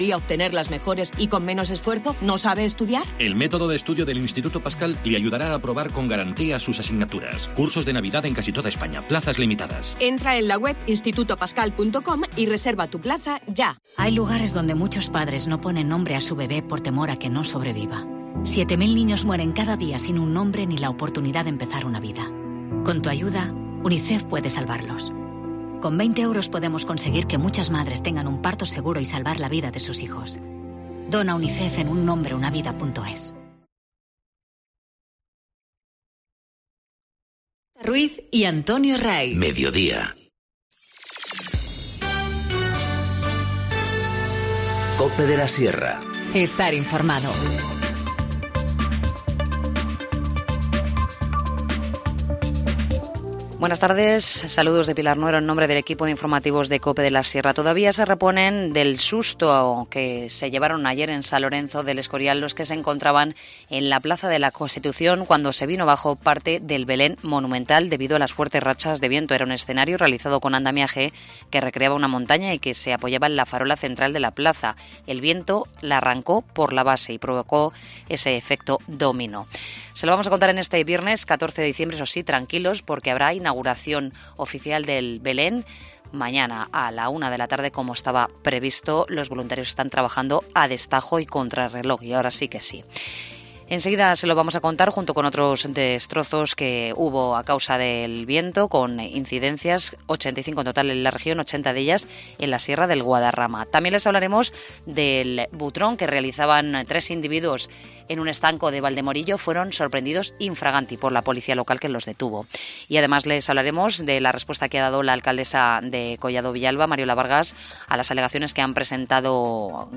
Informativo Mediodía 14 dic- 14:20h